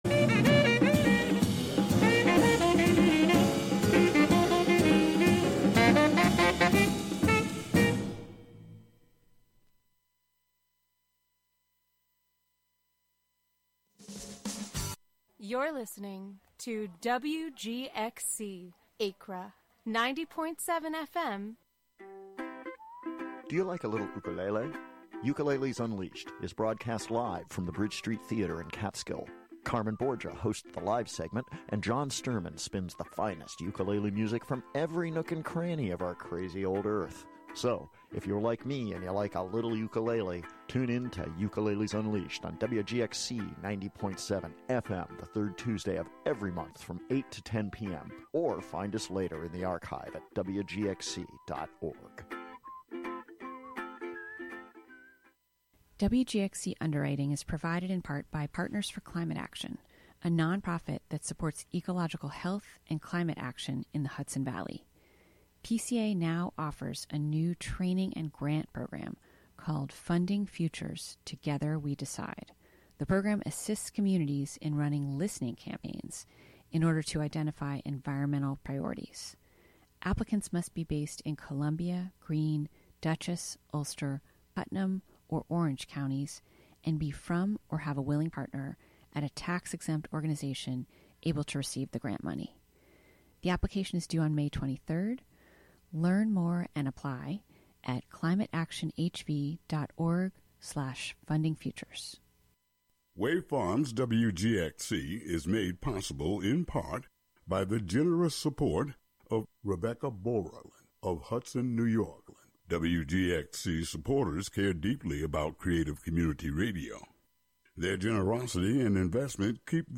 A SPLEN-DID hour of sound and music inspired by the recombinant qualities of food with occasional conversations about milk.